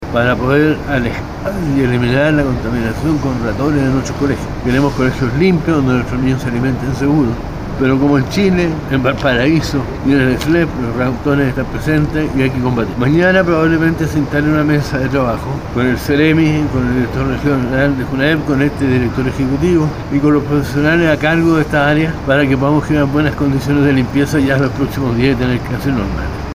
Por su parte, el director ejecutivo del Slep de Valparaíso, Pablo Mecklenburg, afirmó que los ratones están presentes en varios lugares y hay que combatirlos.